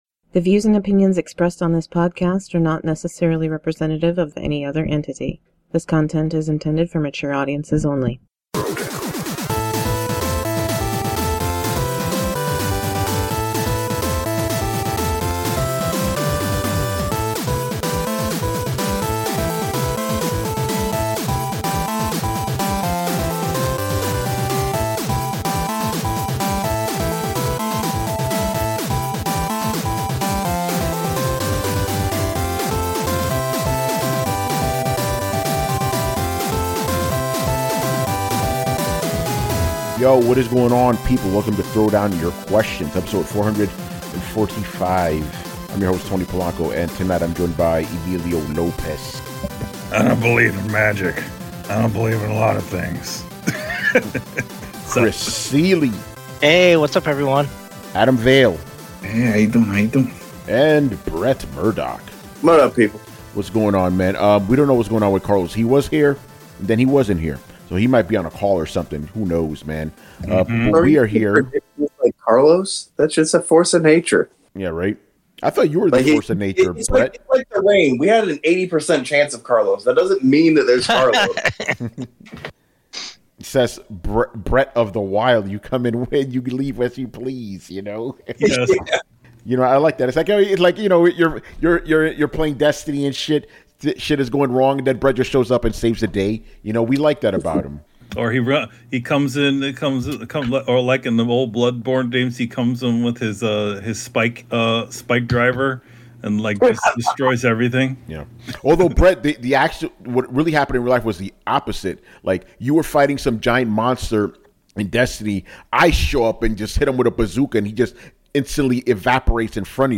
intro and outro music